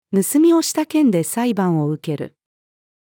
盗みをした件で裁判を受ける。-female.mp3